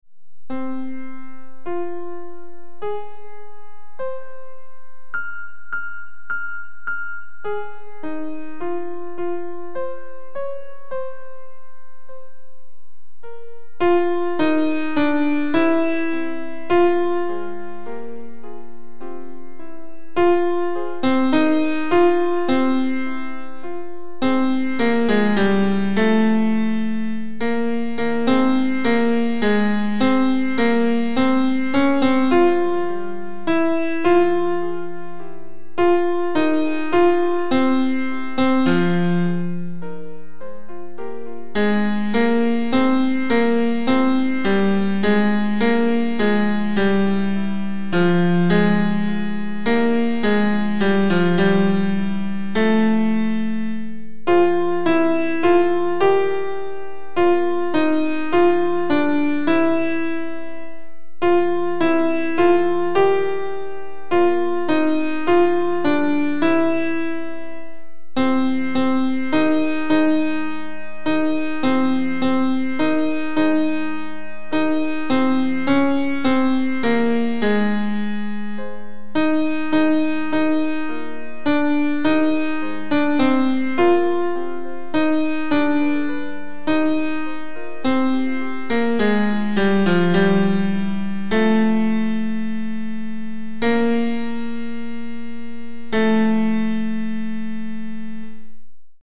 Sound – Tenor.mp3
Victoria_Tenor.mp3